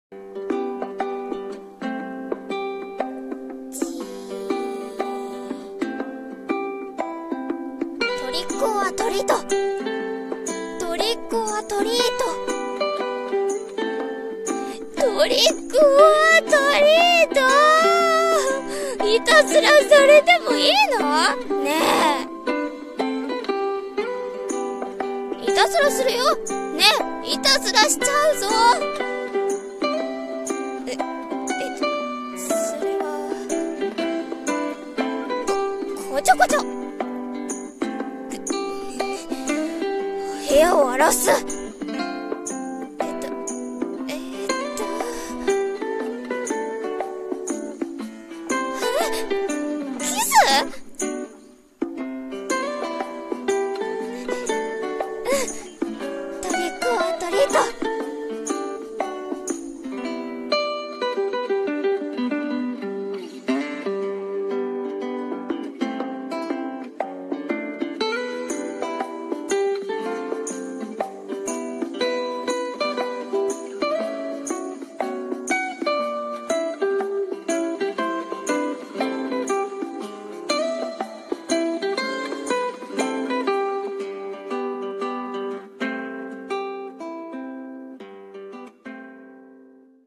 【声劇】トリック オア トリート